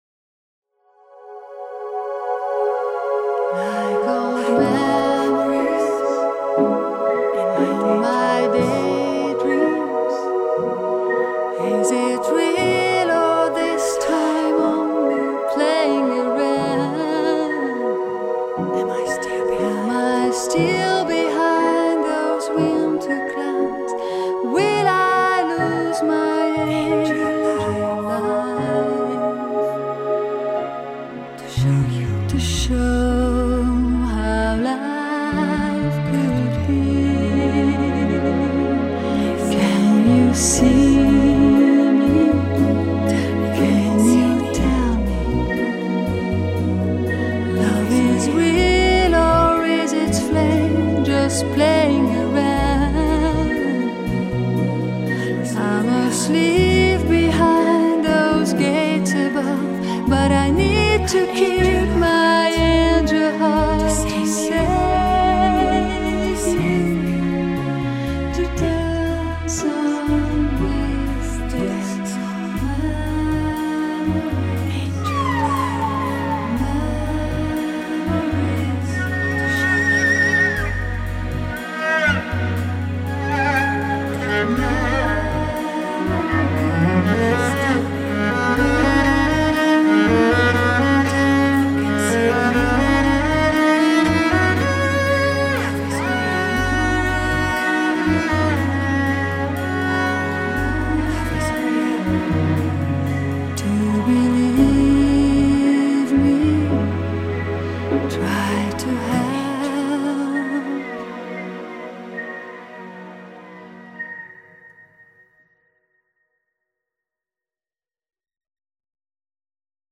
Ez a CD a régiek hangulatától eltérően könnyed popzene lesz.